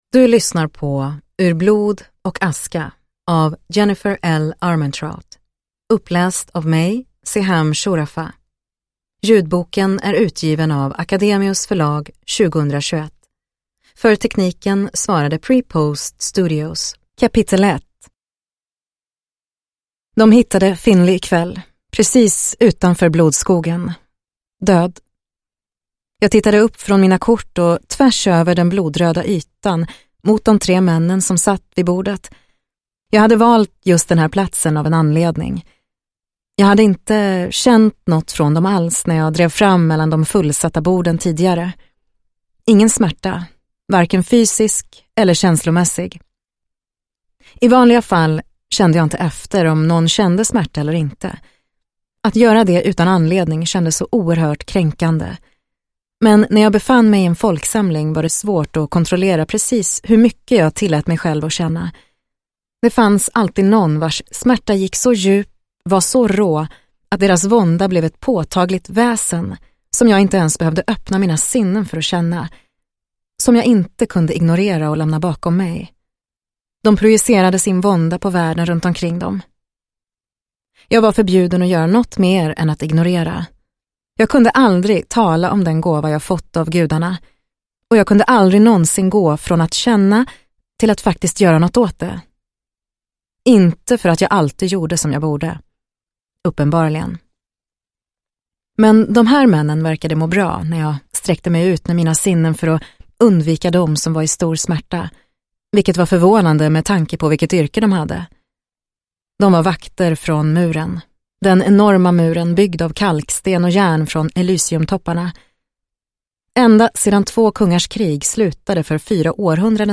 Ur blod och aska – Ljudbok – Laddas ner